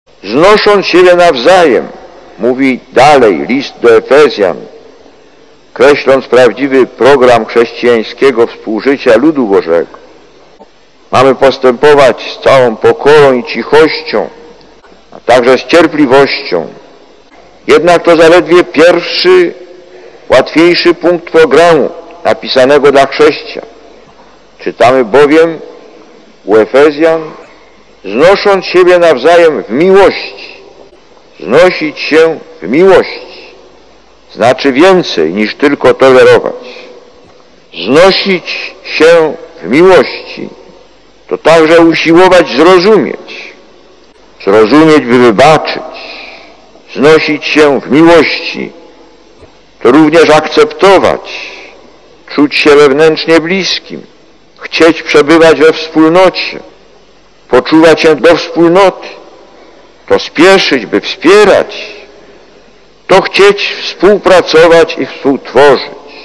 Lektor: Z przemówienia podczas nabożeństwa ekumenicznego (Warszawa, 9.06.1991 -